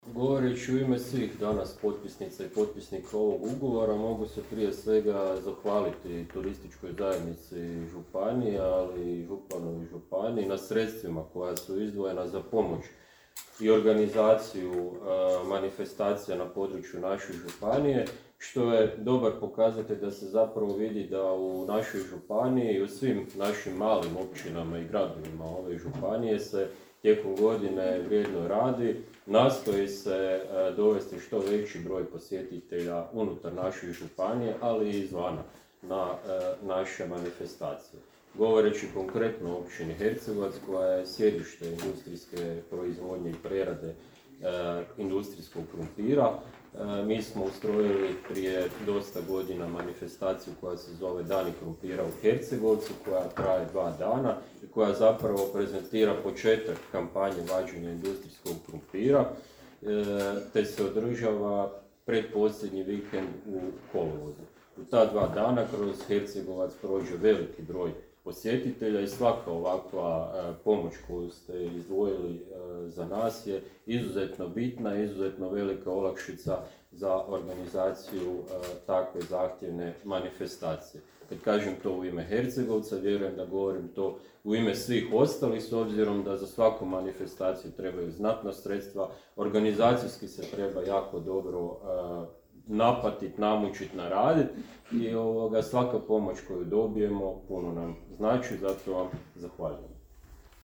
U Bjelovaru je početkom svibnja održano potpisivanje ugovora s prijaviteljima za dodjelu potpora regionalnim i lokalnim događajima u 2022. godini.